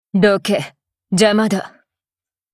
贡献 ） 协议：Copyright，人物： 碧蓝航线:乌尔里希·冯·胡滕语音 您不可以覆盖此文件。